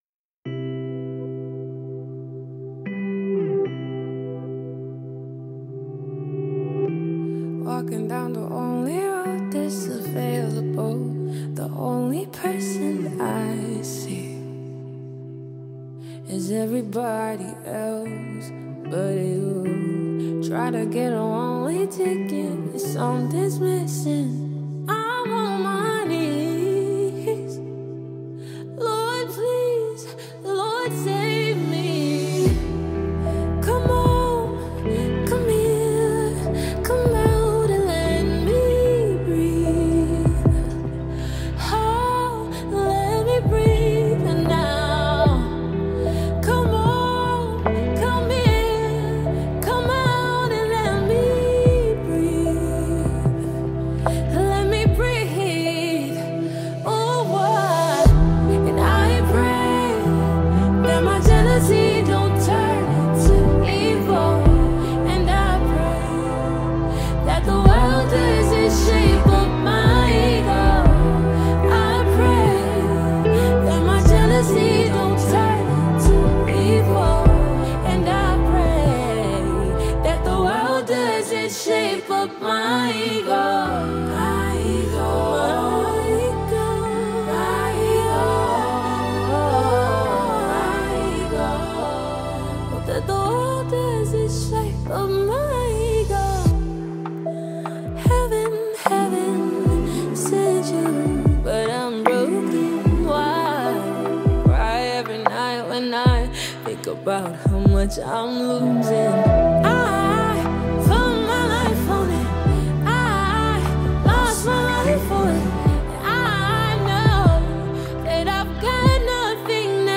Afro-fusion
genre-bending offering